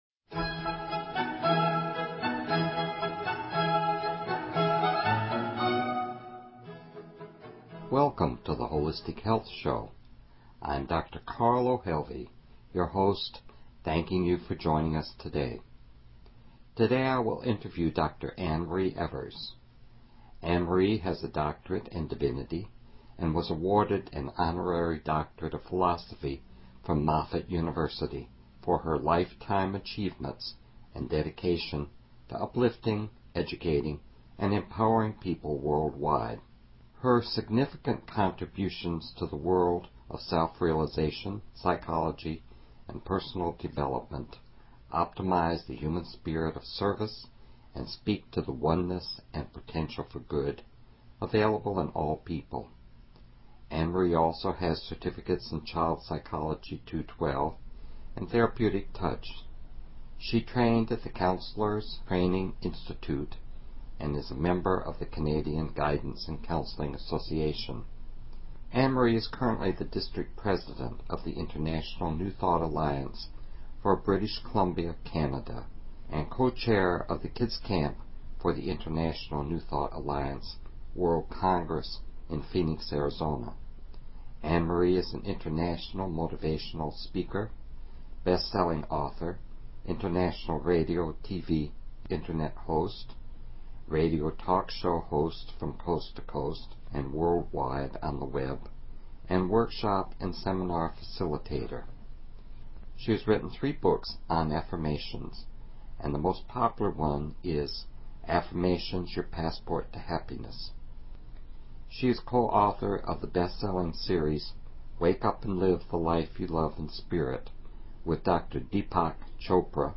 Talk Show Episode, Audio Podcast, The_Holistic_Health_Show and Courtesy of BBS Radio on , show guests , about , categorized as
Interview with Experts on Affirmations and Visualization